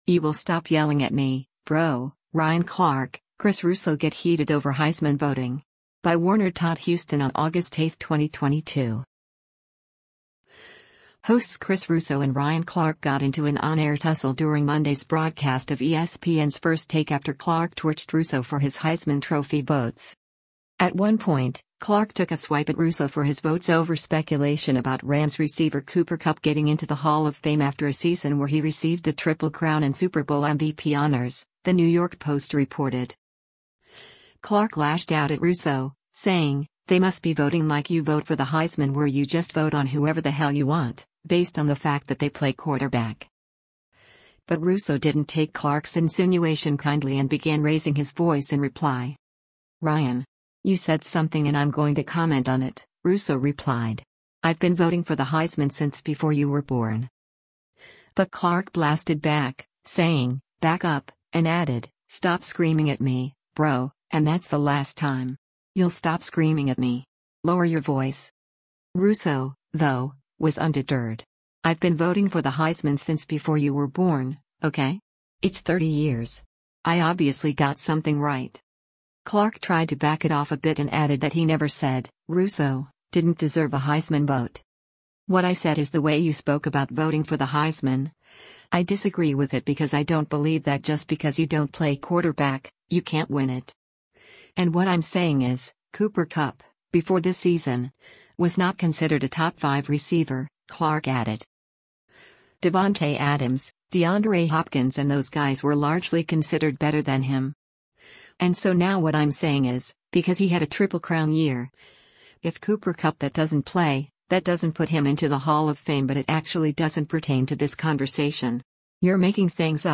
Hosts Chris Russo and Ryan Clark got into an on-air tussle during Monday’s broadcast of ESPN’s First Take after Clark torched Russo for his Heisman Trophy votes.
But Russo didn’t take Clark’s insinuation kindly and began raising his voice in reply.